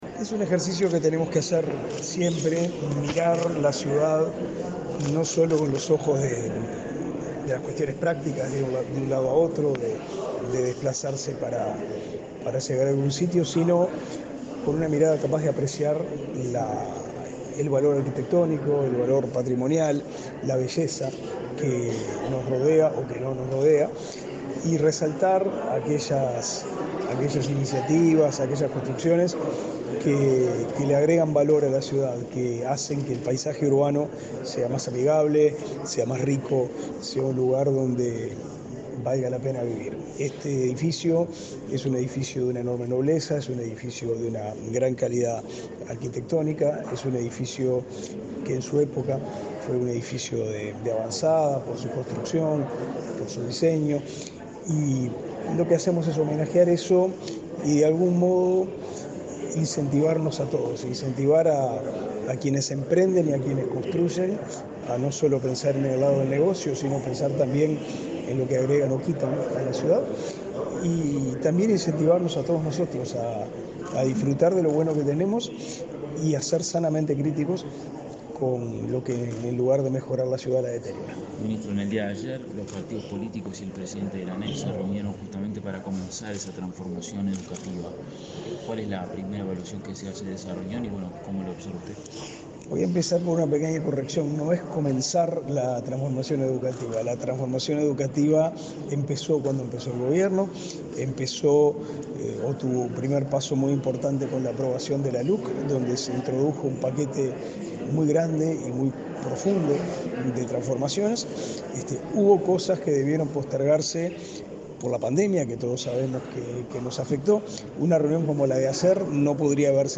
Declaraciones a la prensa del ministro de Educación y Cultura, Pablo da Silveira
El Ministerio de Educación y Cultura, a través de la Comisión del Patrimonio Cultural de la Nación, declaró Monumento Histórico Nacional al edificio Artigas, ubicado en la intersección de las calles Rincón y Treinta y Tres, en el barrio Ciudad Vieja, de Montevideo. Luego, el ministro Pablo da Silveira, dialogó con la prensa.